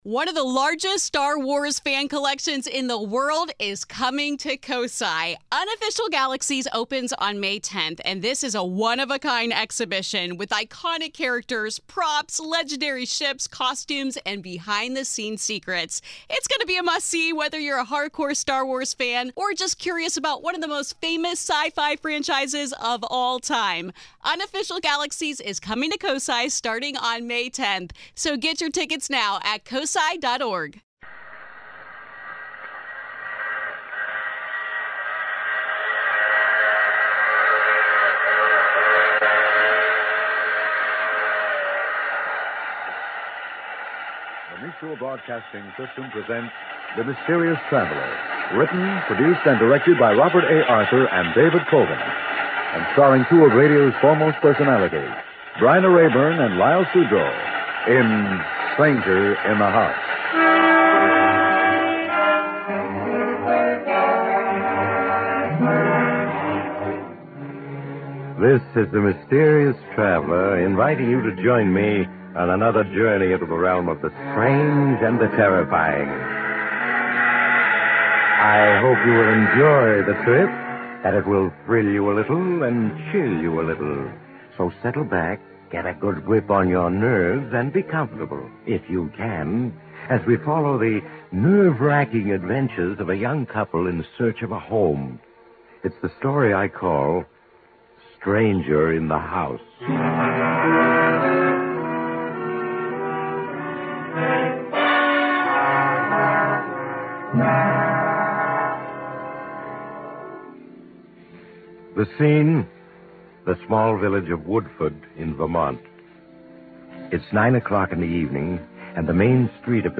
On this episode of the Old Time Radiocast we present you with two stories from the classic radio program The Mysterious Traveler!